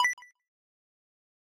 beep_power.ogg